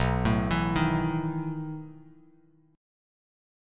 B11 Chord
Listen to B11 strummed